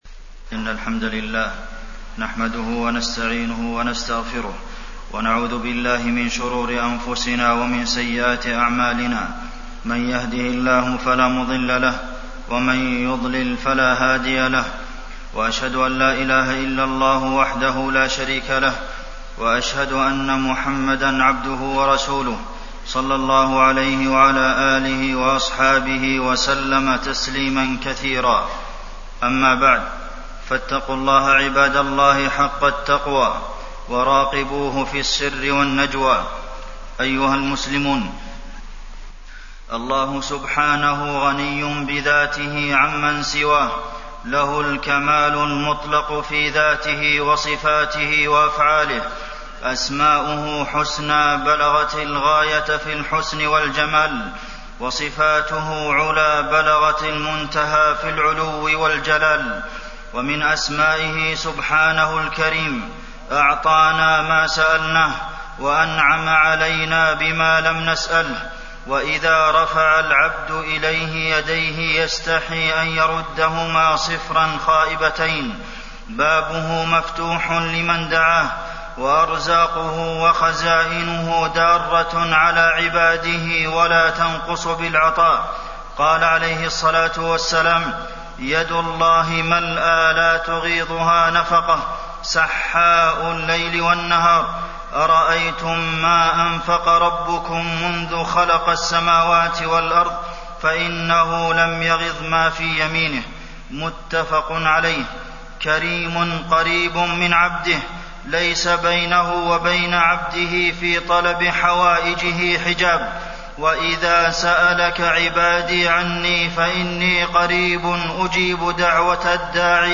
تاريخ النشر ٦ رجب ١٤٣١ هـ المكان: المسجد النبوي الشيخ: فضيلة الشيخ د. عبدالمحسن بن محمد القاسم فضيلة الشيخ د. عبدالمحسن بن محمد القاسم سؤال الله من فضله